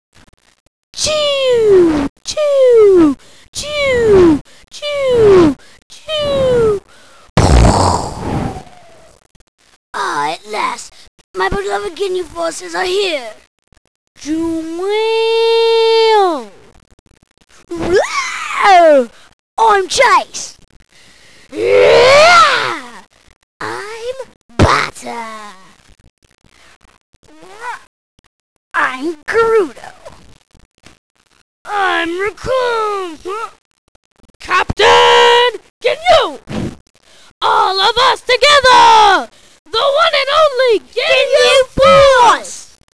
Oh, and don't mail me saying that the scripts are not exact, they're not meant to be.